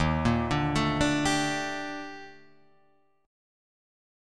EbM#11 chord